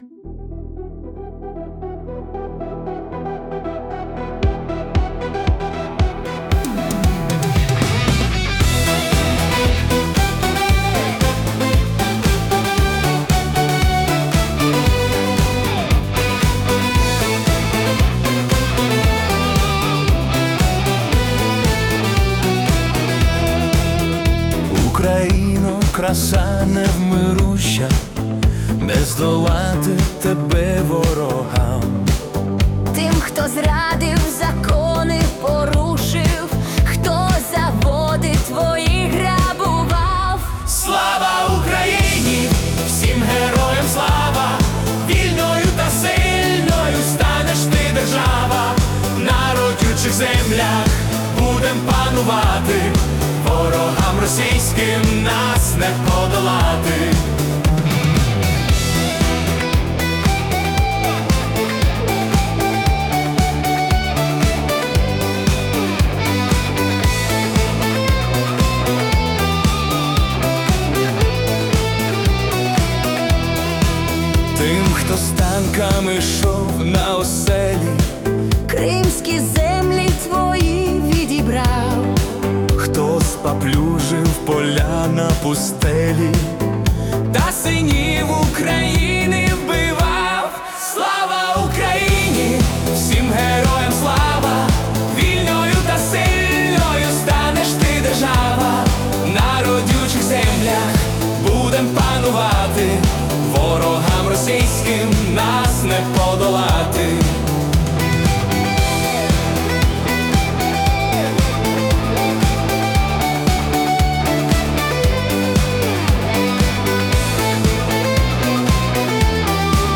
Patriotic Italo Disco / Anthem